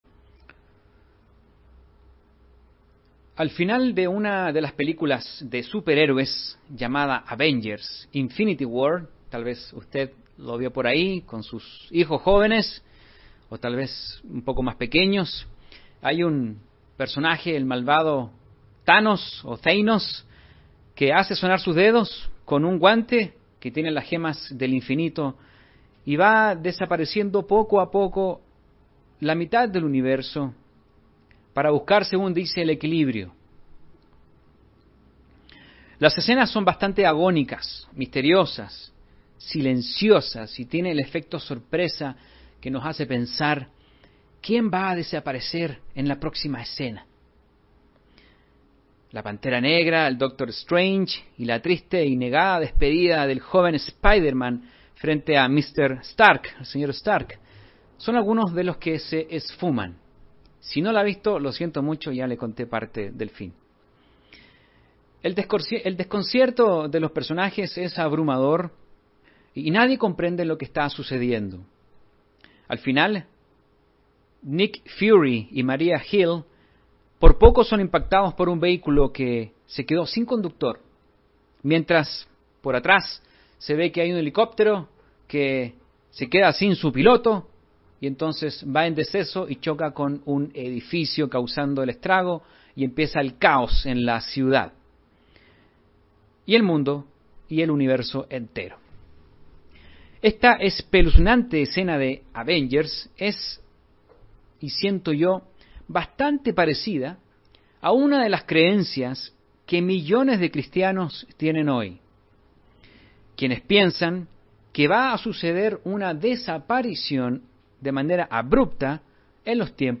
Mensaje entregado el 9 de enero de 2021.